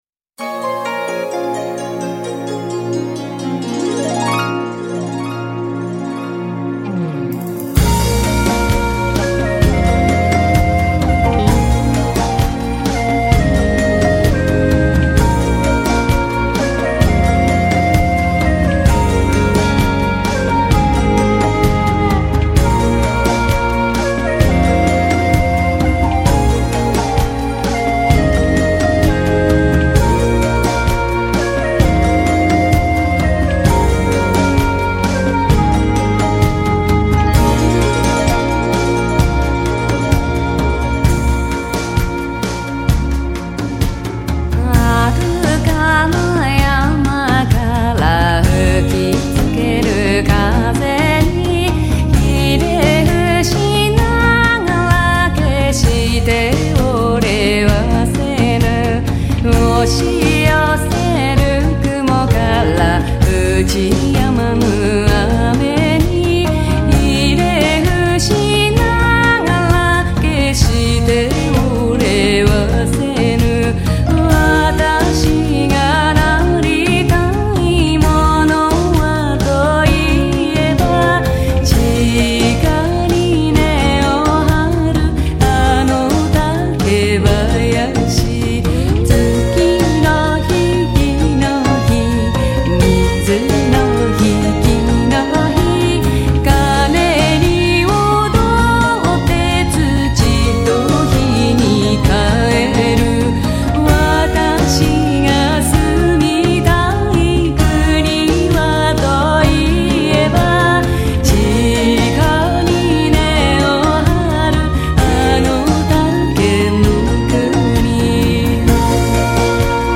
原唱版
她的基本风格是以叙述的歌唱曲风并搭配简单的吉他伴奏。